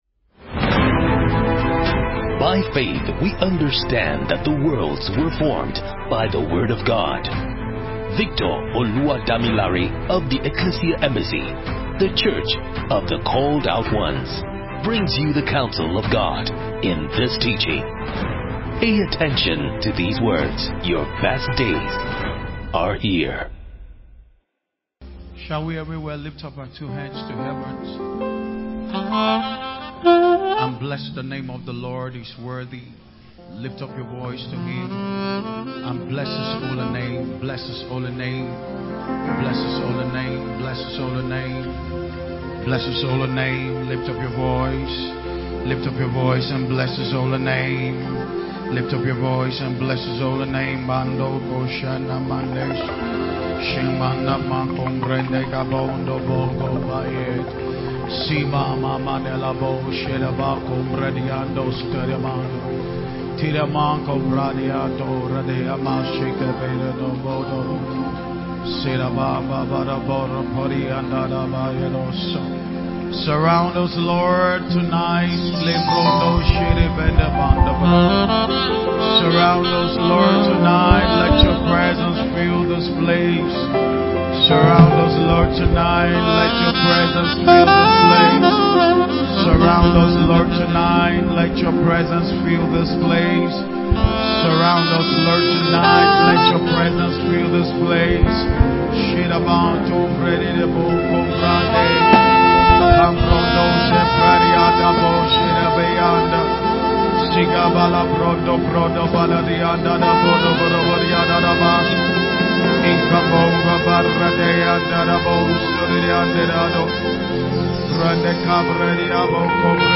Thursday Service